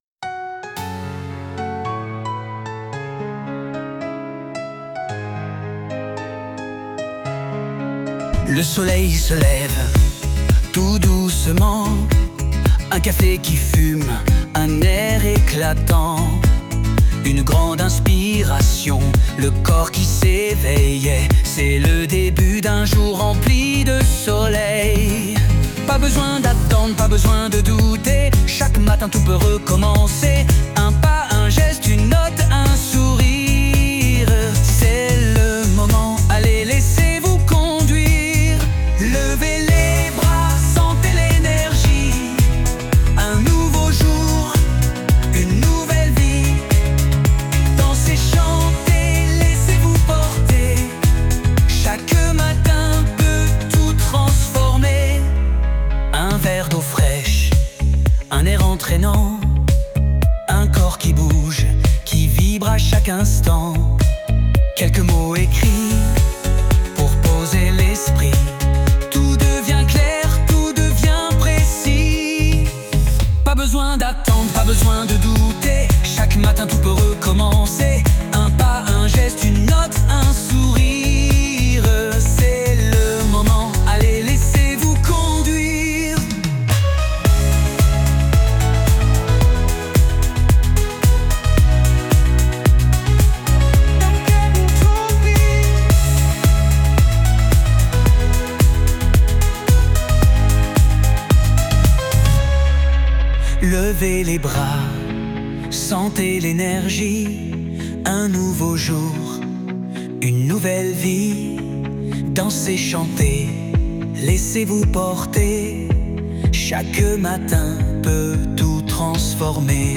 Préparez-vous à accueillir des journées plus sereines et épanouissantes, en commençant par écouter cette chanson pleine d’énergie créée spécialement pour vous :